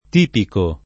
tipico [ t & piko ]